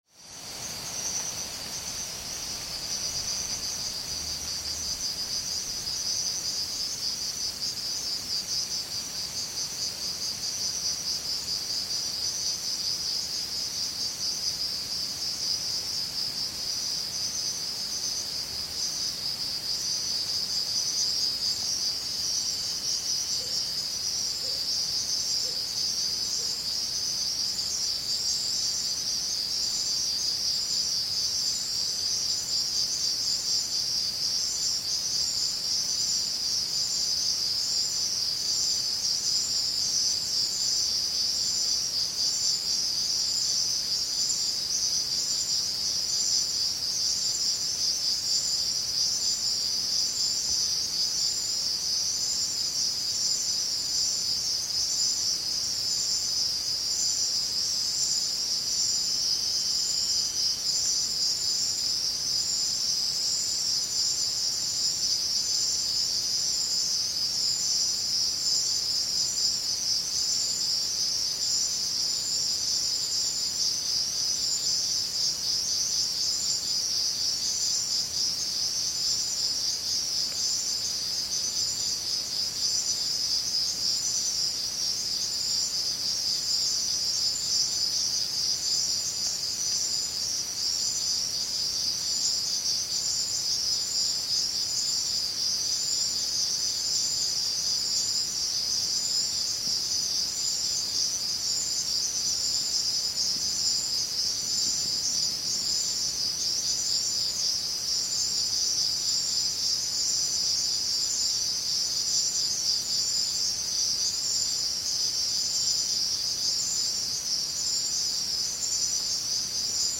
Night insects at Calakmul
Stereo 48kHz 24bit.
UNESCO listing: Ancient Maya City and Protected Tropical Forests of Calakmul, Campeche